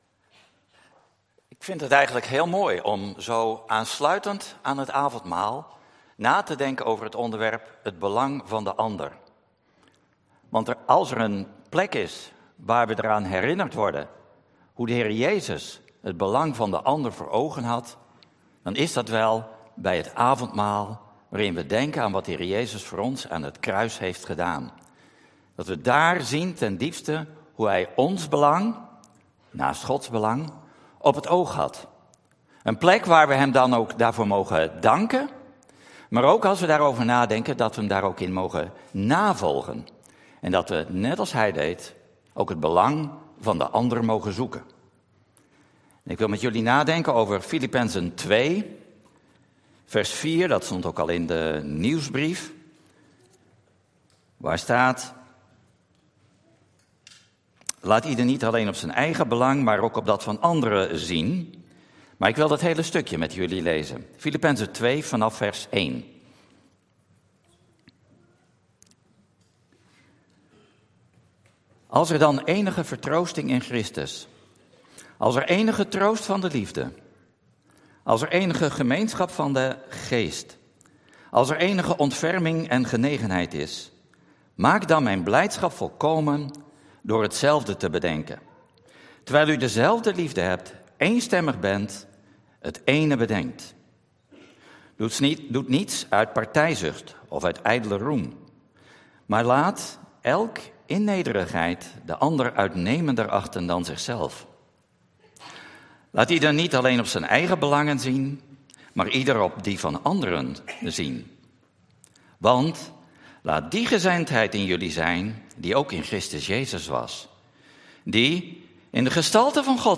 Toespraak 29 januari: Het belang van de ander - De Bron Eindhoven